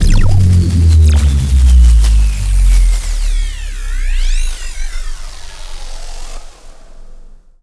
sci-fi_electric_pulse_power_down_03.wav